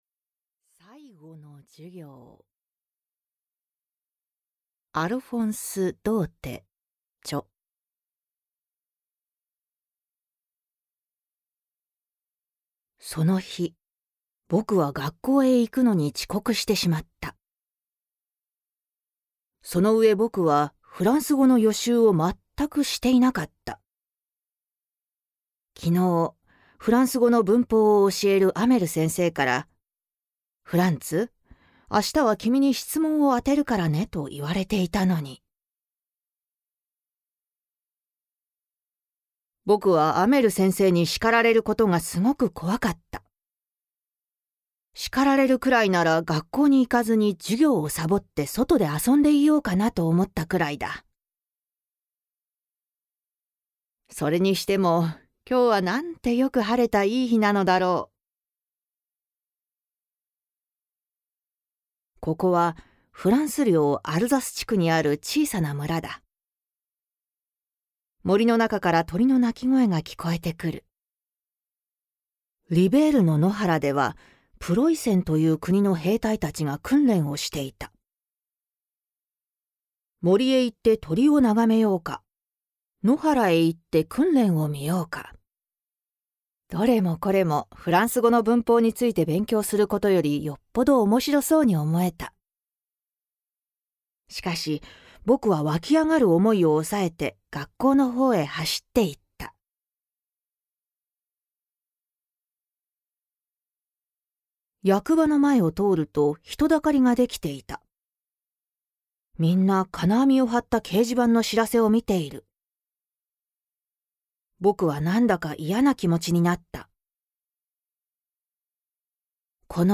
[オーディオブック] 最後の授業（こどものための聴く名作 32）